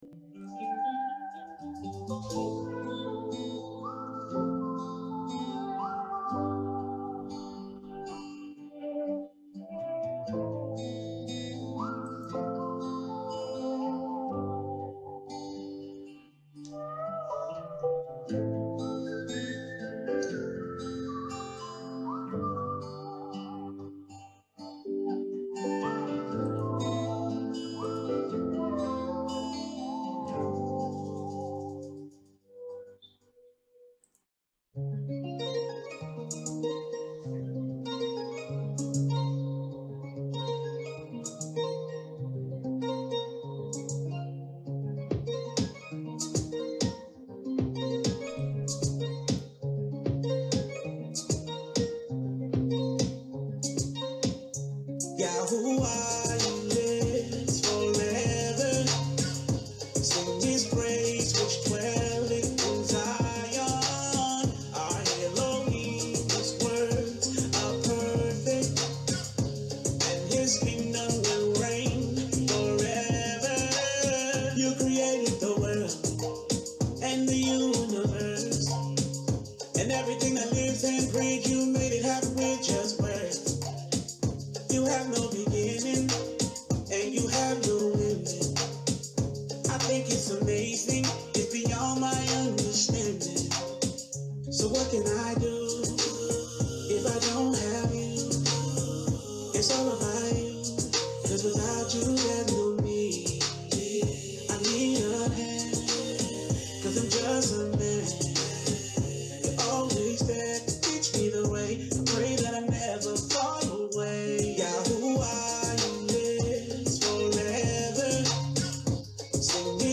Tuesday-night-bible-study-THE-WORD-OF-YAH-4.mp3